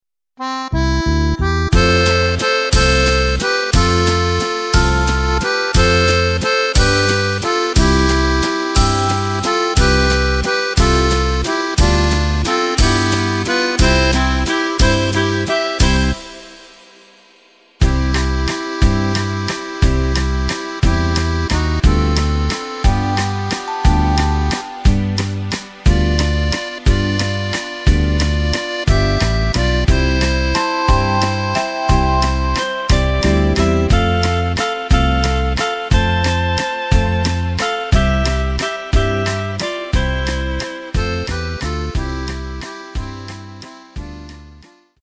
Takt:          3/4
Tempo:         179.00
Tonart:            C
Walzer (Oldie) aus dem Jahr 1960!
Playback mp3 Demo